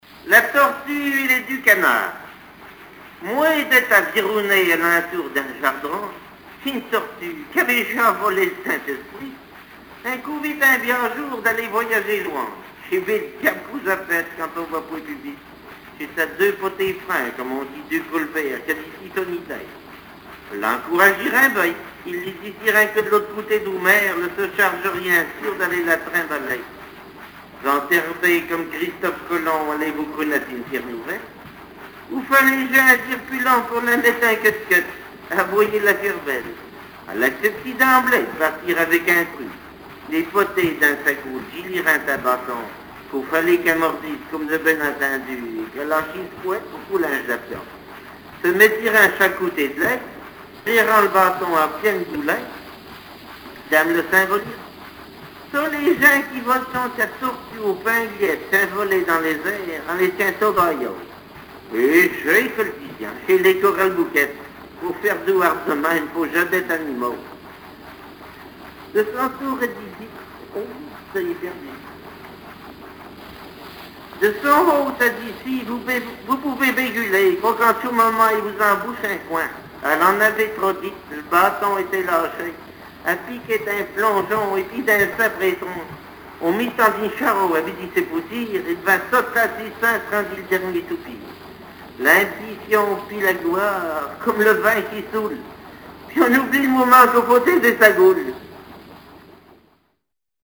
Langue Maraîchin
Genre fable
Catégorie Récit